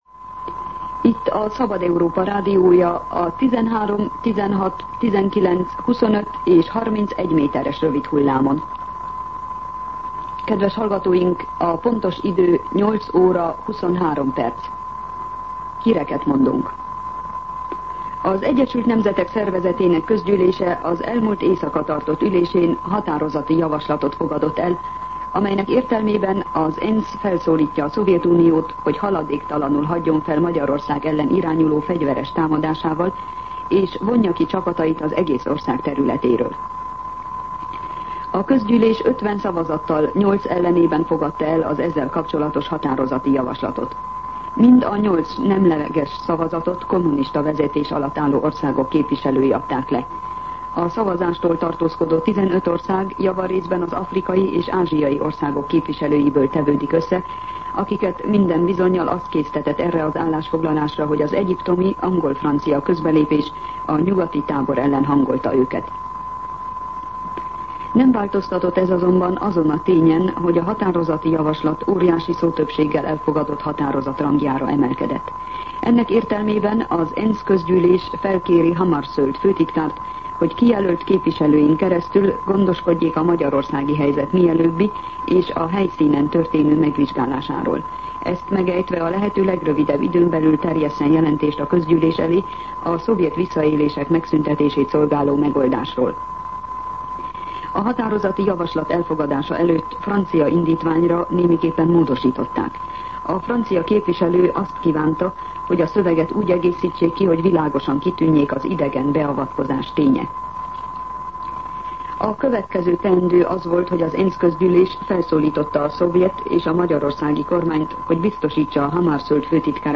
08:23 óra. Hírszolgálat